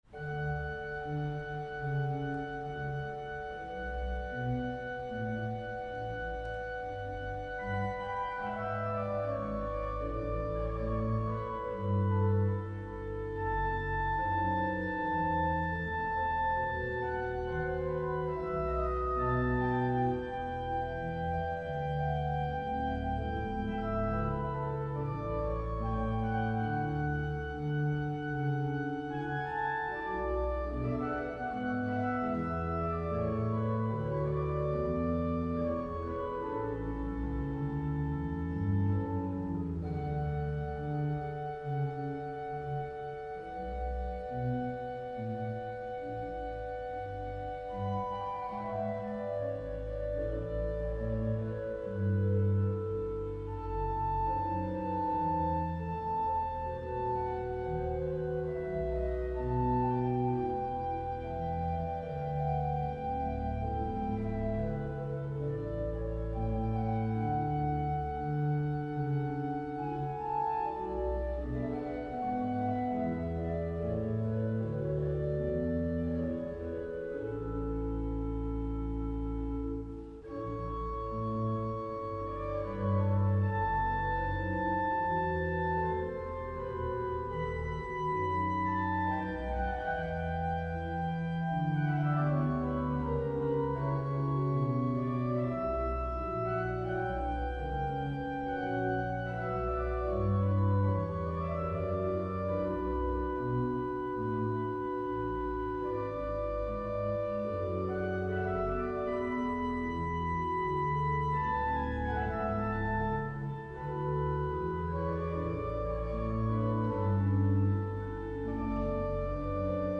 Freiburg, Vinzentinerinnenkirche, FISCHER&KRÄMER-Orgel
Mechanische Spiel- und Registertraktur, Schleiflade.
Orgelbearbeitung Gordon Phillips (1908-1991)